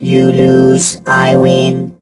rick_kill_vo_07.ogg